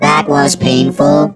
rick_hurt_02.wav